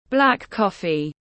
Cà phê đen tiếng anh gọi là black coffee, phiên âm tiếng anh đọc là /blæk ˈkɒfi/
Black coffee /blæk ˈkɒfi/